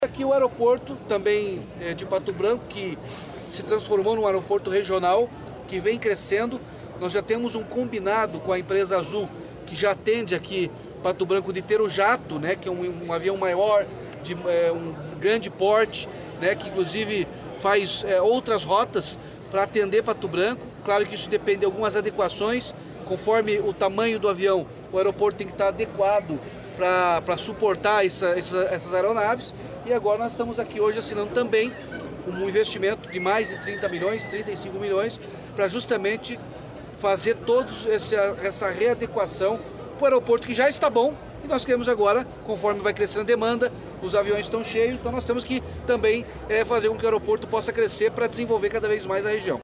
Sonora do governador Ratinho Junior sobre o novo terminal do Aeroporto de Pato Branco